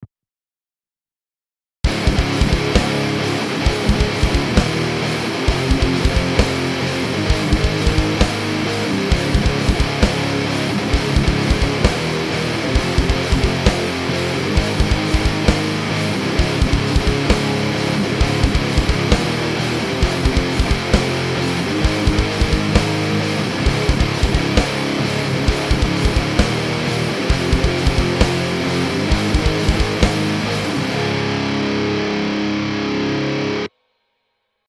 The guitar used was a schecter hotrod 39 with a GFS Power rail in the bridge.I have recently changed the strings, but I found out after putting them on, that the music store sold me D'addario jazz strings instead of regular electric guitar strings.
I used the lepou lecto model with a random free impulse of a mesa cab w/ sm57 I found in a google search, the tse 808 lite, and a noise gate.
For bass, I used an octave with my guitar, and used the Ola method to recording bass at home, with the 2 tracks and distortion. Drums were from Sennheiser's Drummica.
This is as loud as I could get it without too much compression. I also used a little multi band.